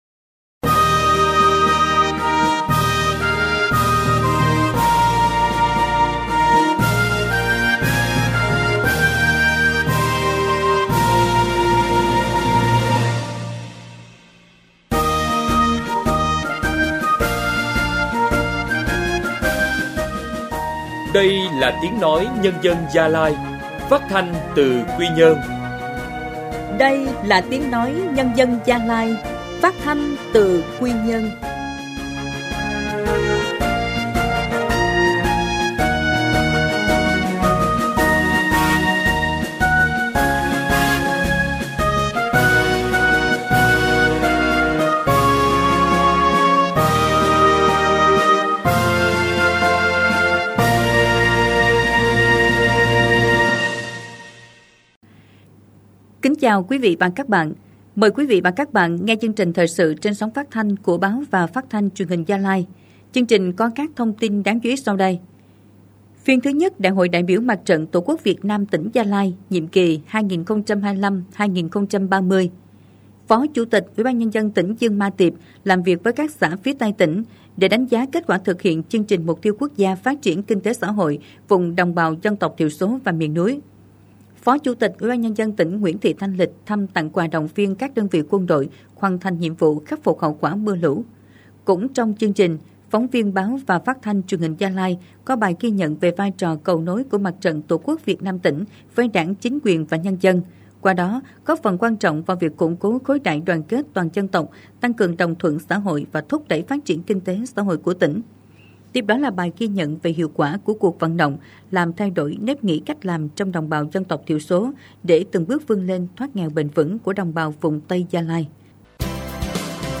Thời sự phát thanh sáng